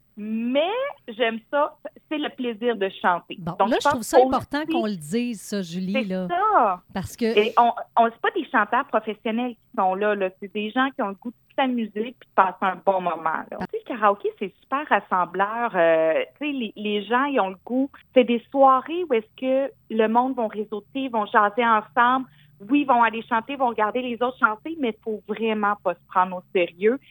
La mairesse de Granby, Julie Bourdon, a lancé l’invitation à la population lundi dans le cadre de l’émission M105 Le Matin :